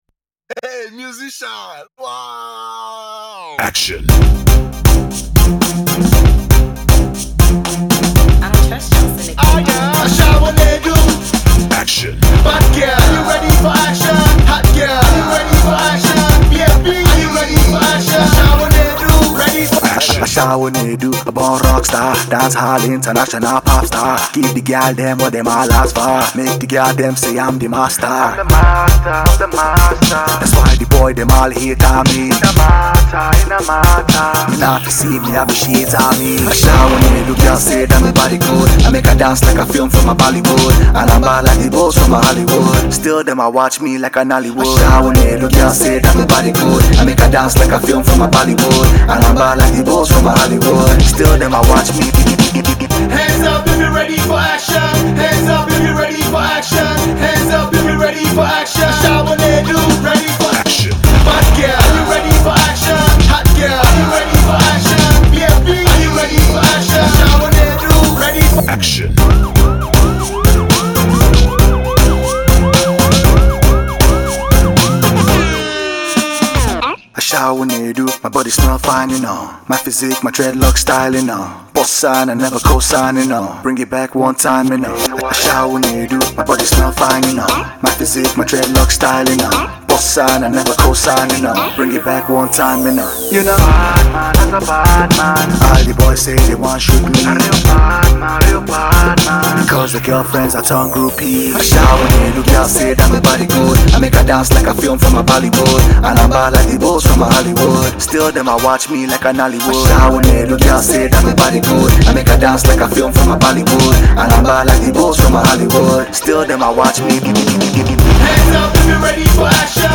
dance-hall crooner
dance-hall